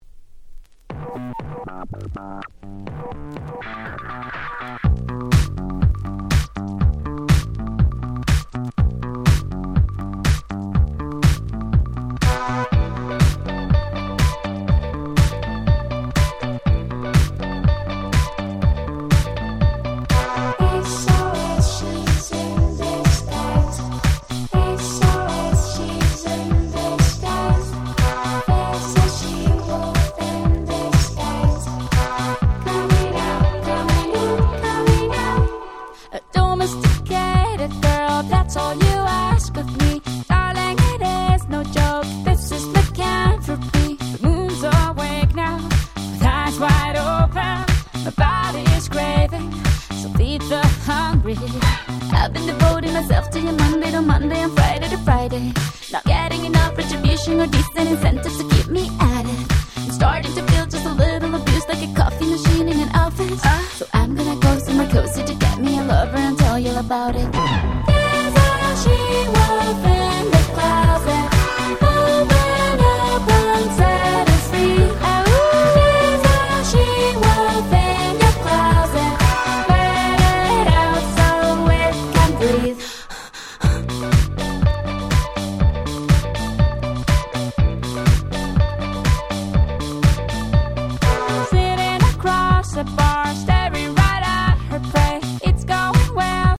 09' Super Hit R&B / Pops !!